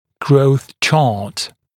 [grəuθ ʧɑːt][гроус ча:т]карта роста